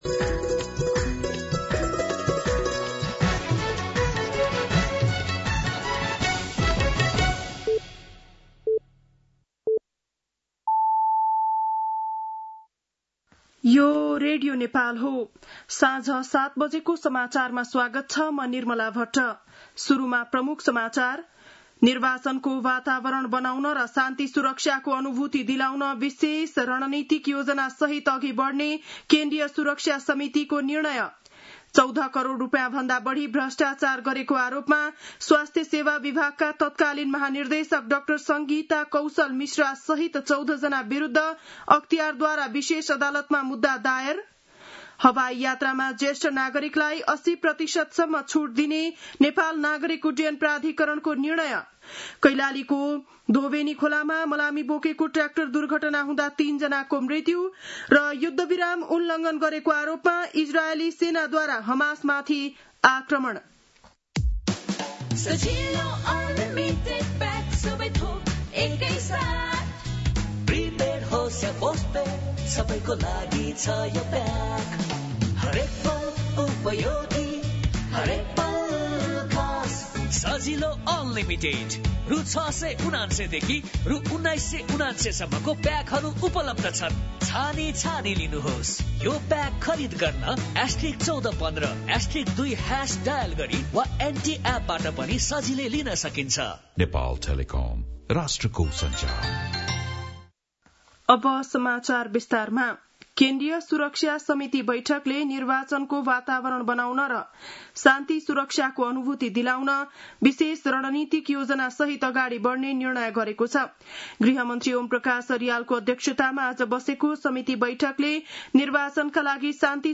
बेलुकी ७ बजेको नेपाली समाचार : २ कार्तिक , २०८२
7.-pm-nepali-news-.mp3